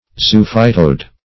Search Result for " zoophytoid" : The Collaborative International Dictionary of English v.0.48: Zoophytoid \Zo*oph"y*toid\, a. [Zoophyte + -oid.]
zoophytoid.mp3